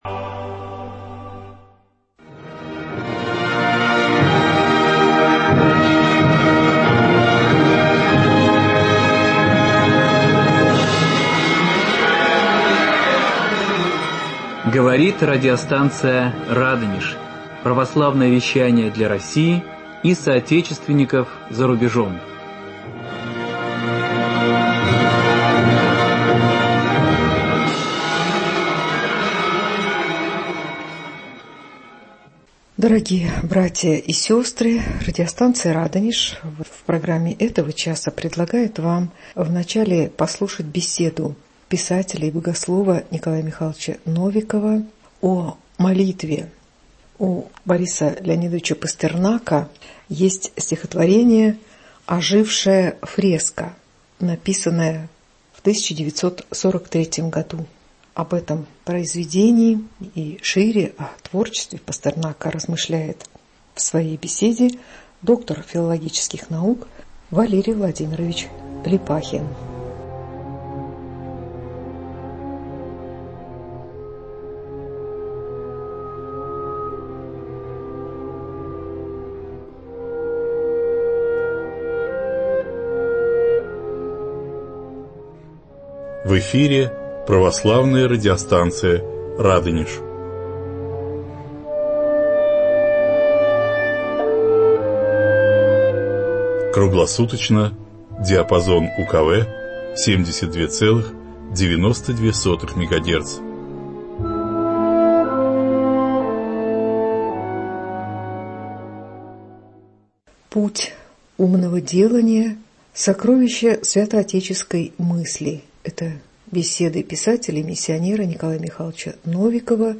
Православные венгры в Древней Руси. Беседа